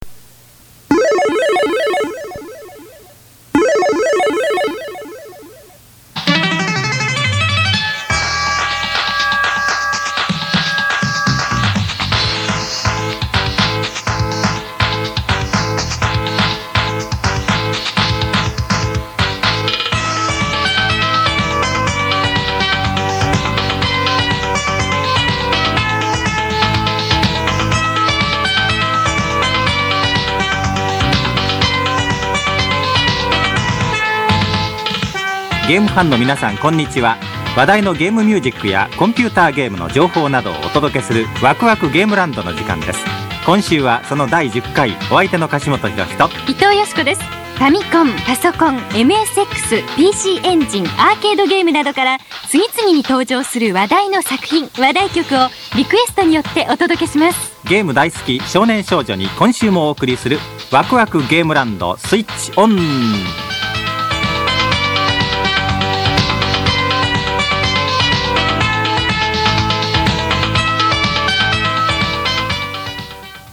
「わくわくゲームランド」　　　　ゲーム音楽専門のラジオ番組が１９８８年秋にスタートした。